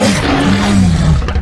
dragonroar.ogg